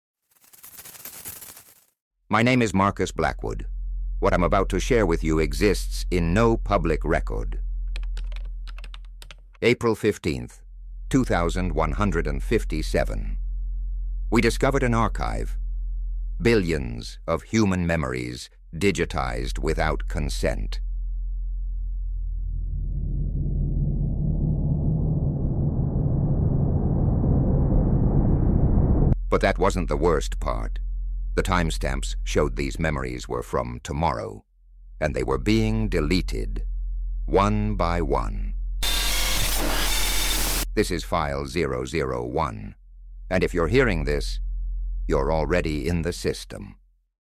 Library of Audio Fiction Podcasts